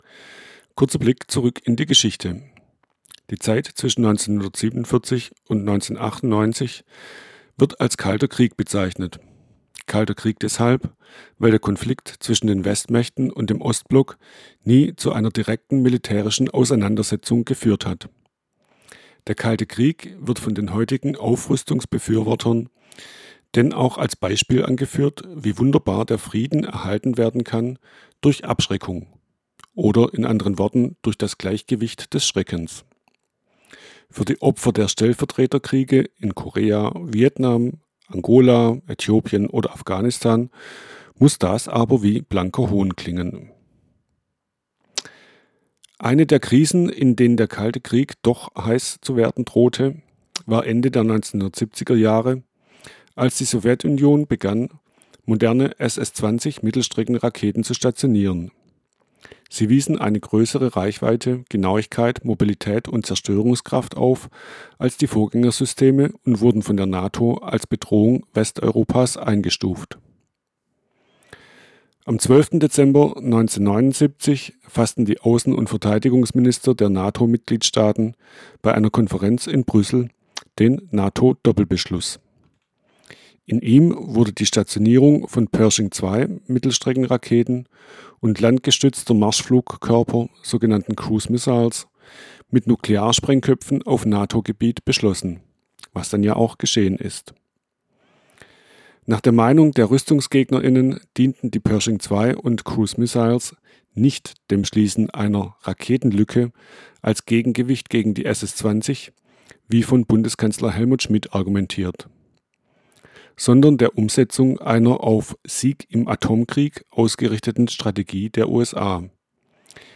Text-to-Speech-Technologie von Piper und Thorsten-Voice